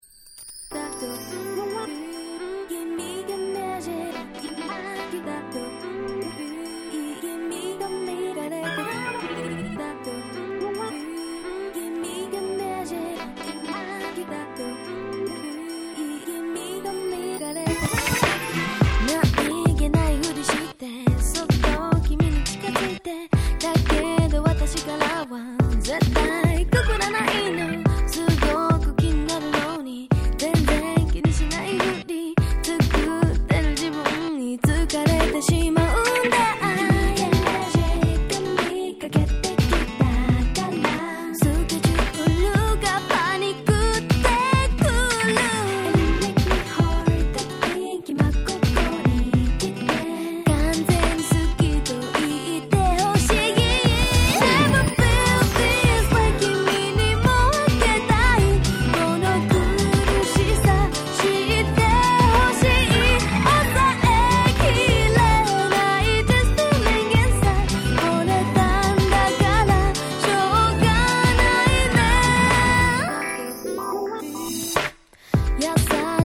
02' Nice Japanese R&B !!
当時のメインストリームな感じはしっかり取り入れつつもキャッチーで凄く良い曲！